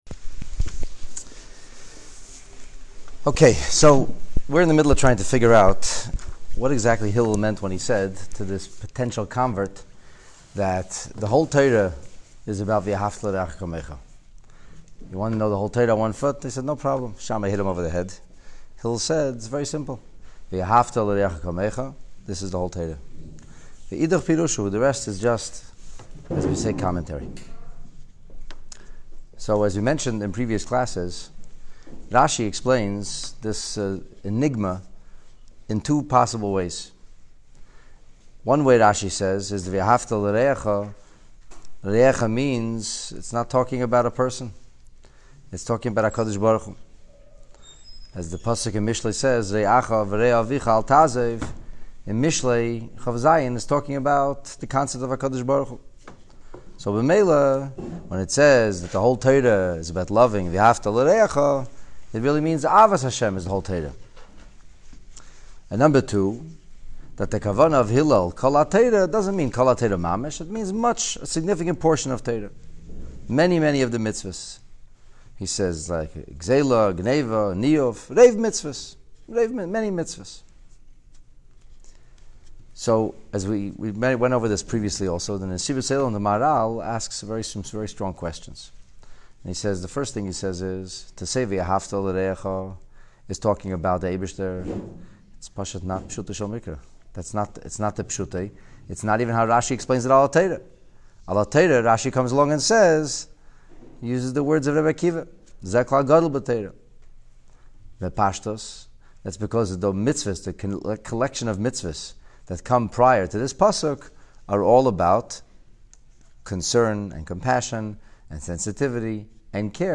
Tanya Classes